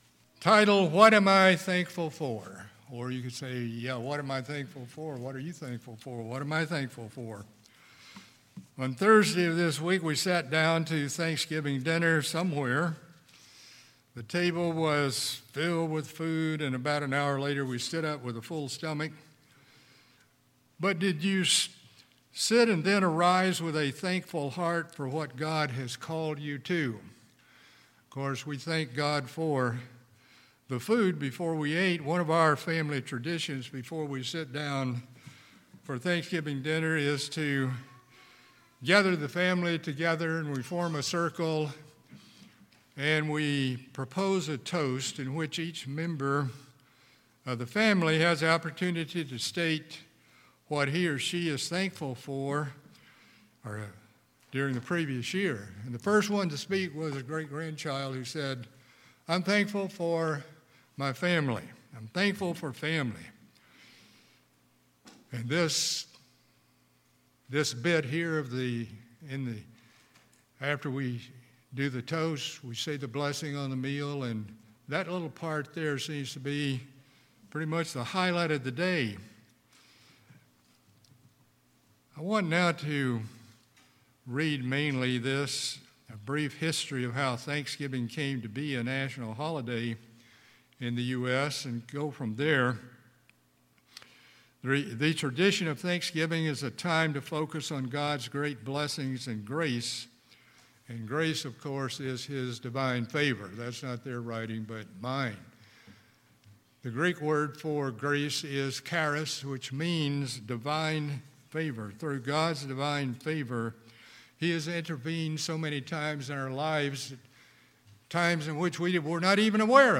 In this sermon we examine the importance of a thankful heart in God’s eyes and what we should be thankful for every day.